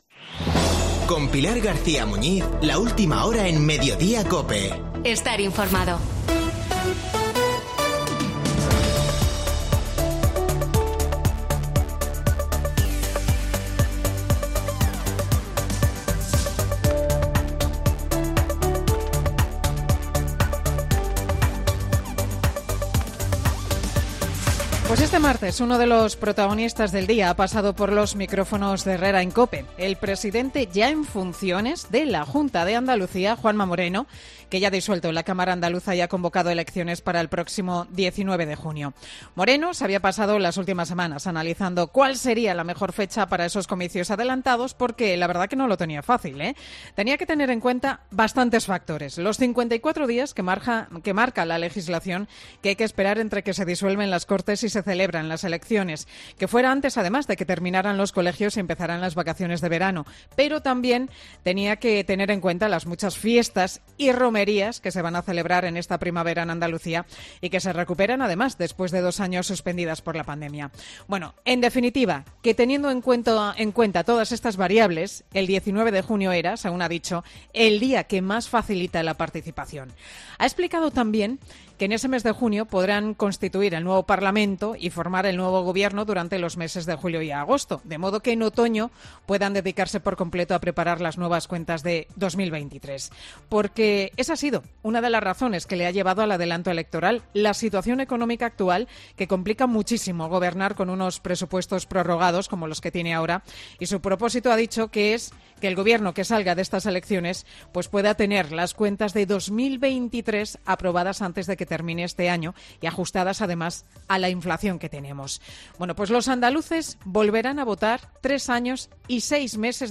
Monólogo de Pilar García Muñiz
El monólogo de Pilar García Muñiz, en 'Mediodía COPE'